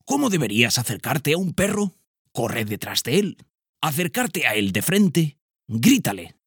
TEST PERROS DESCONOCIDOS-Narrador-02_0.mp3